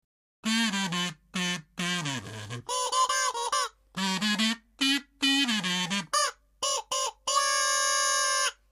イヤハーイ笛とカズ―がひとつになりました　カズ―で歌いイヤハーイ笛の音を途中でいれると　たのしい演奏になります